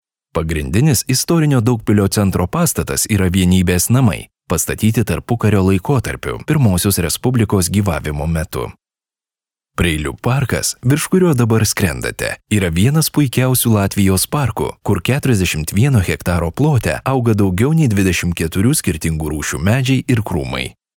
Lithuanian voiceovers
Lithuanian voice talent